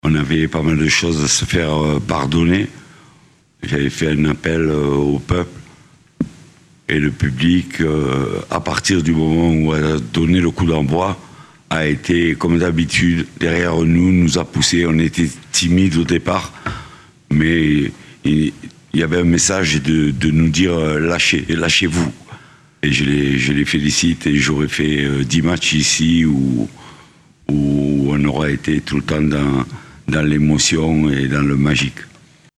son-reaction-gasset-om-80413.mp3